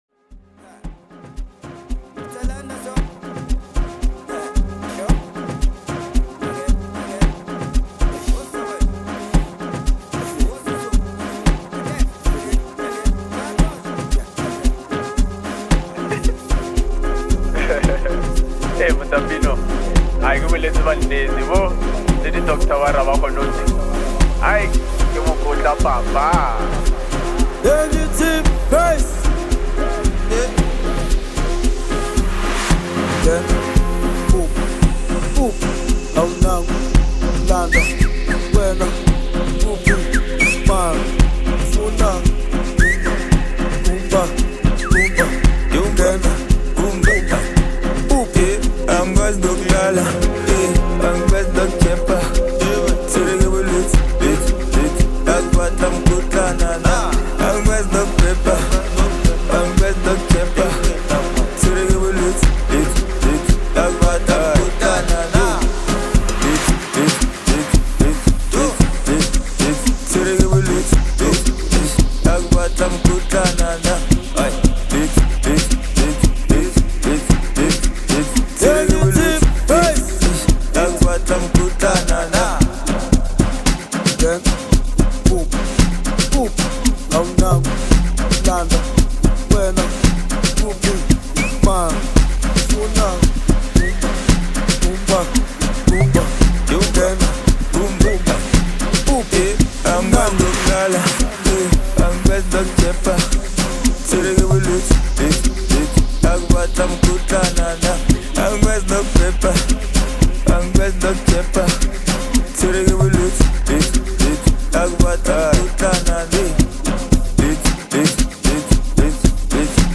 Home » Amapiano » Gqom